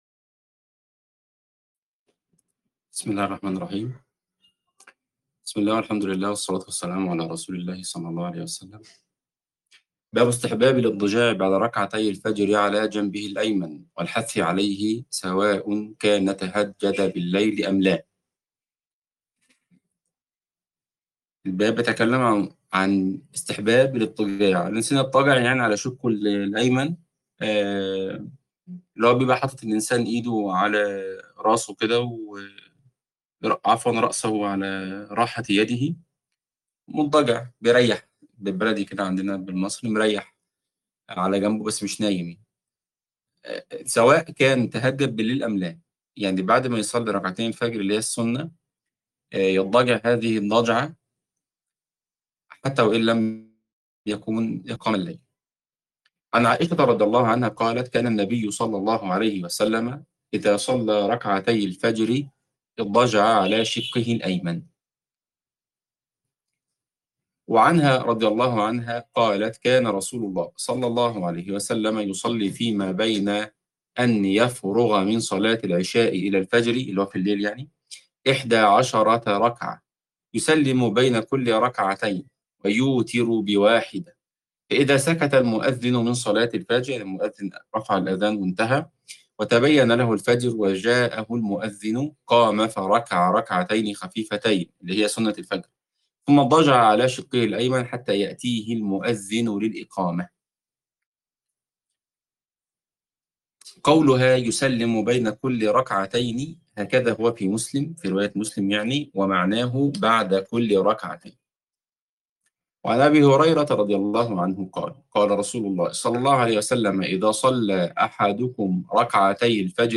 الدرس 32 | دورة كتاب رياض الصالحين